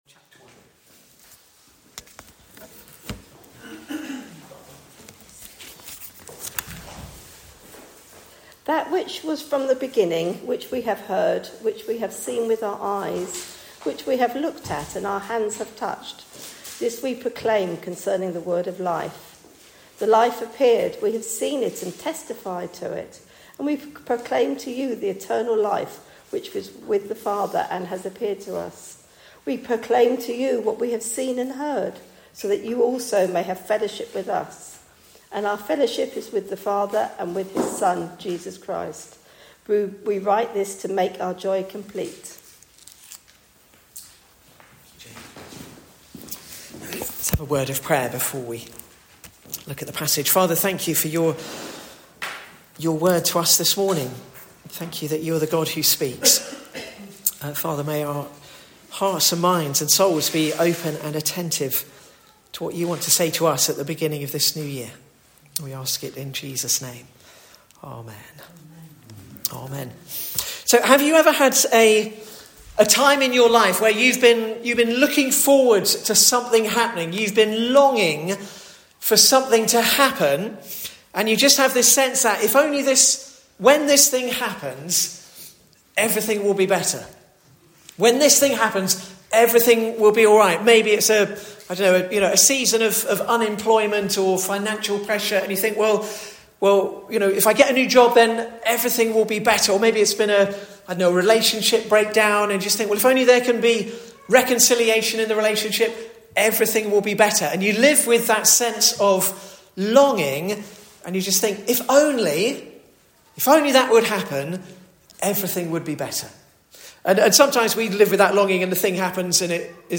Sermons recorded at Bolney Village Chapel in West Sussex